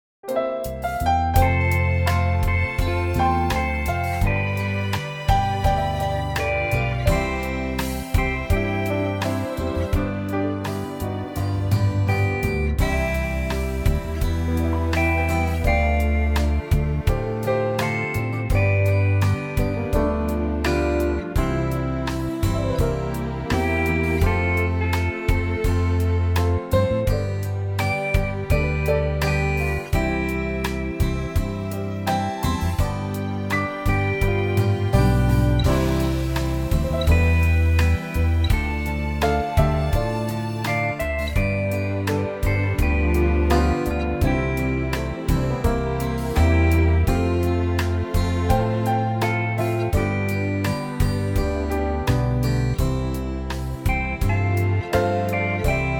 Unique Backing Tracks